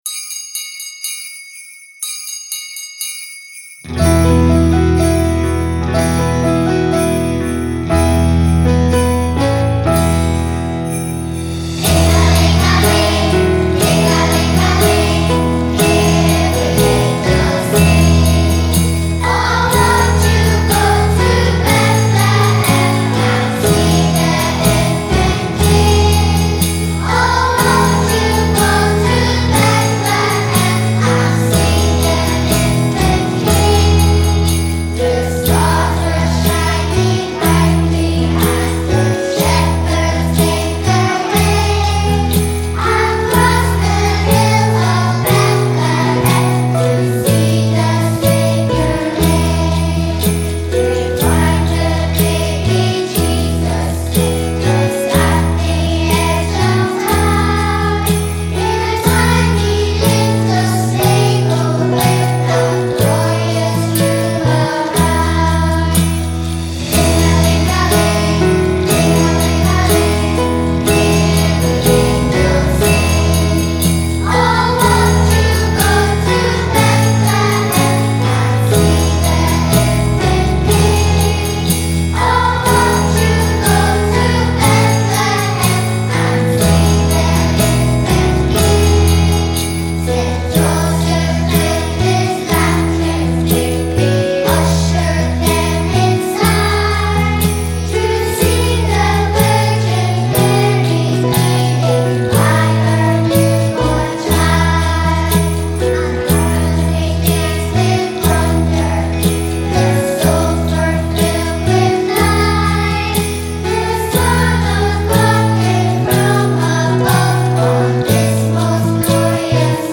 The song was recorded live at the Live Crib.